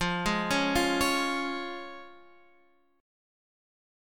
Fm#5 chord